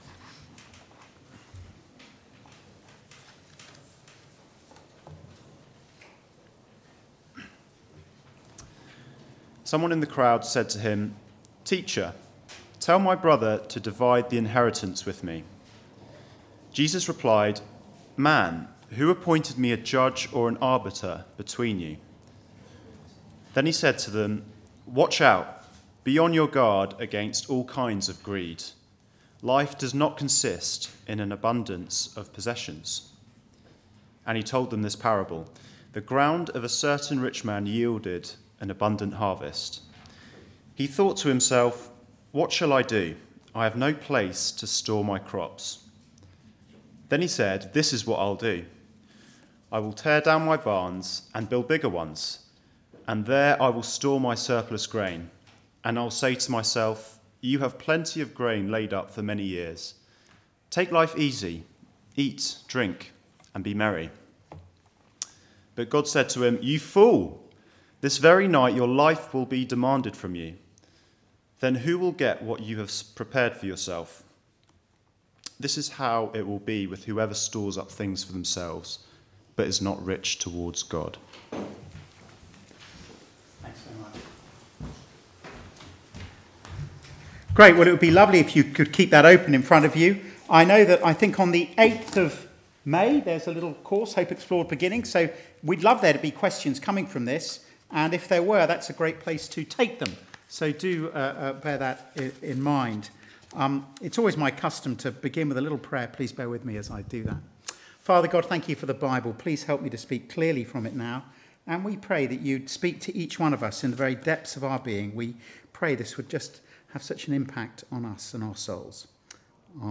Guest Services Passage: Luke 12: 13-21 Service Type: Weekly Service at 4pm « Grace